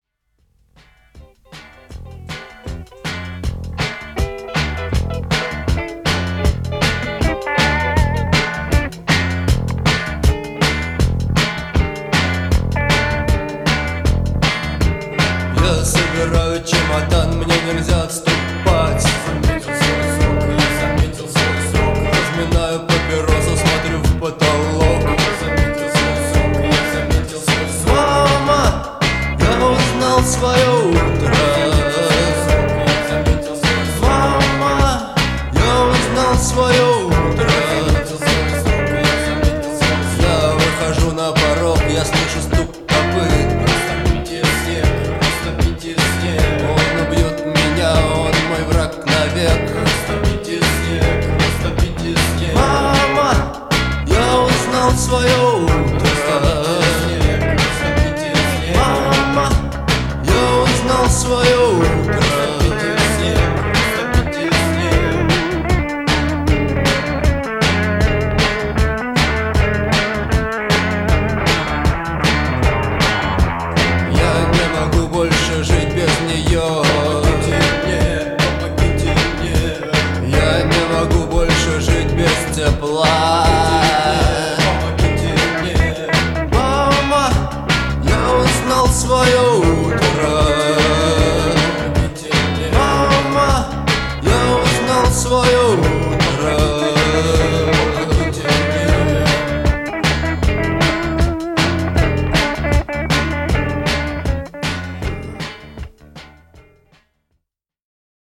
мелодичными гитарными рифами